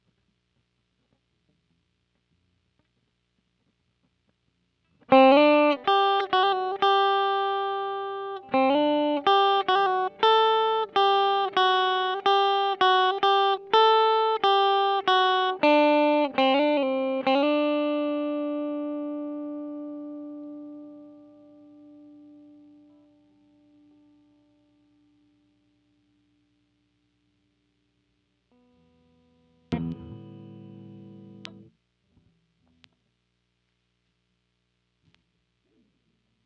Guitar_014.wav